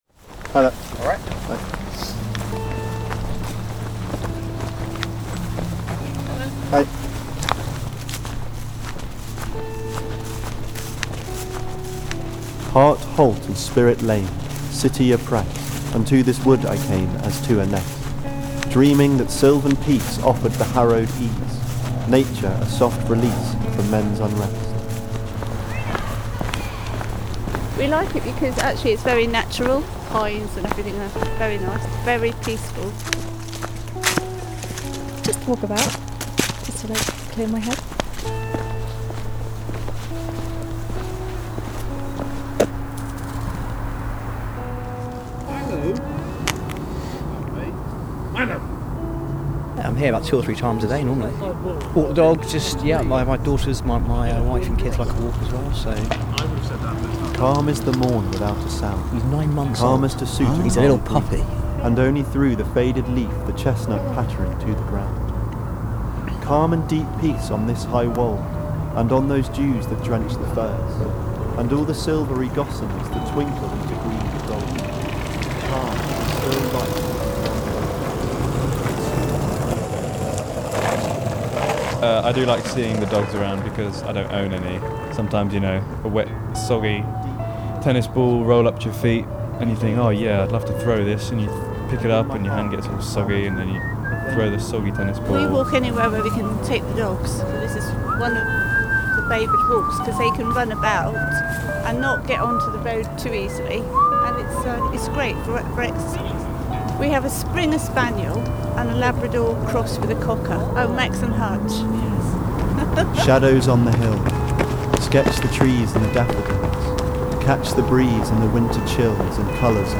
Media and folk perception has created a firm image of what a Cambridge student is. Through the use of sound, music, and interviews with three students this piece seeks to challenge those perceptions of old money, nepotism and unrelatable genius and uncover a much the more diverse and nuanced reality.